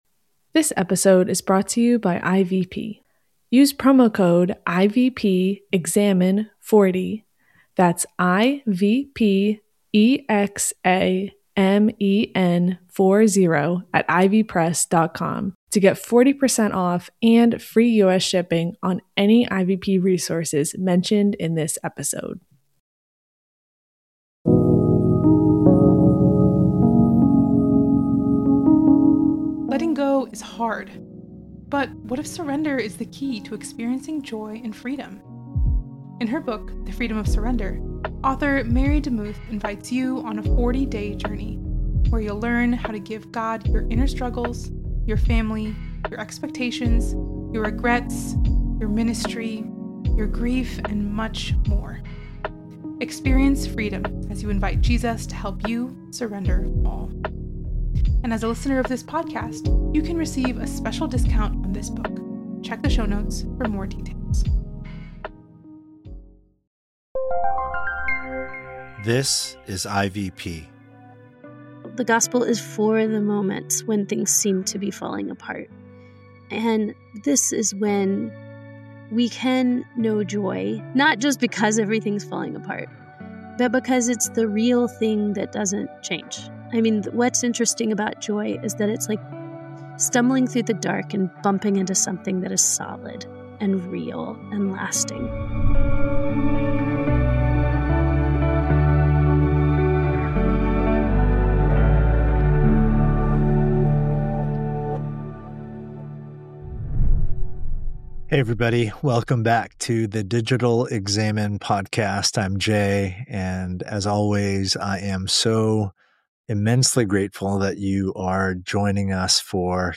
Tune into this conversation for an invitation to discover God’s sacramental joy even on your most ordinary days.